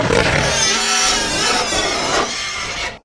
Divergent / mods / Soundscape Overhaul / gamedata / sounds / monsters / chimera / die_3.ogg